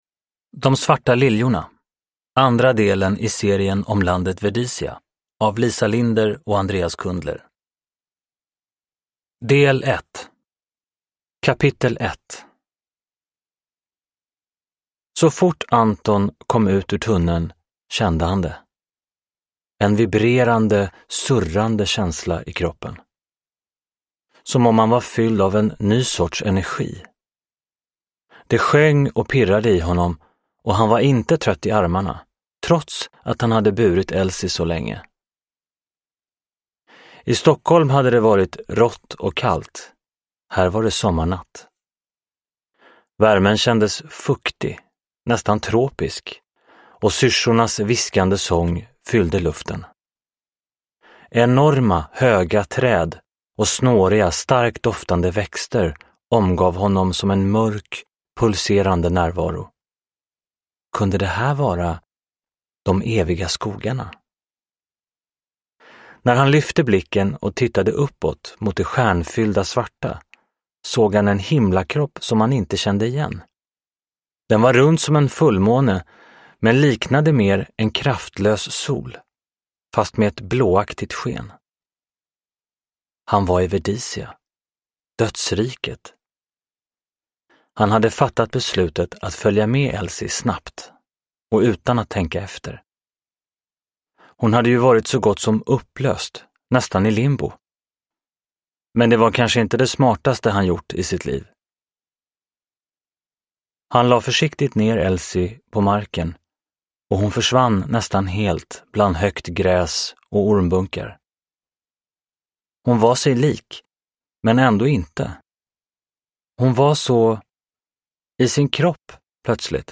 De svarta liljorna – Ljudbok – Laddas ner